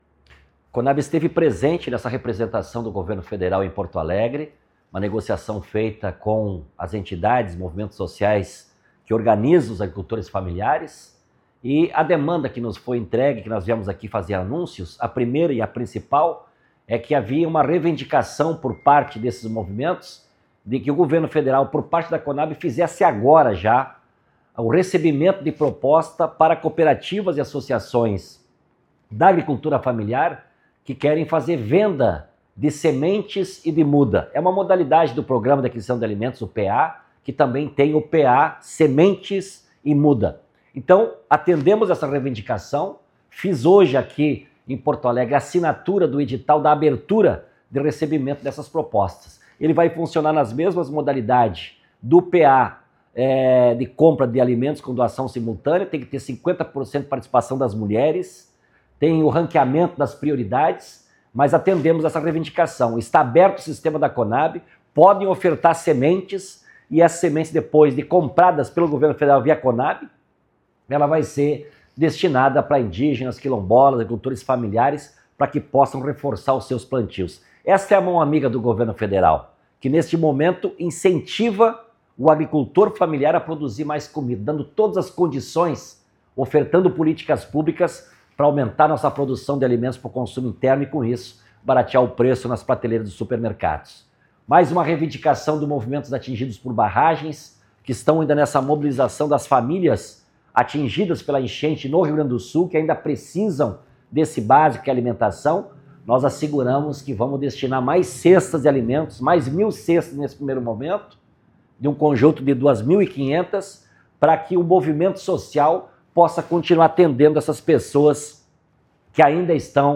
A Companhia Nacional de Abastecimento (Conab) vai comprar e fazer doação de sementes para ajudar agricultores familiares que sofreram com as recentes mudanças climáticas e catástrofes ambientais no Rio Grande do Sul. O anúncio foi realizado na manhã desta quinta-feira (15), em Porto Alegre, pelo presidente da estatal, Edegar Pretto, durante reunião do governo federal com representantes do setor.
Sonora-Edegar-Pretto-Sementes-.mp3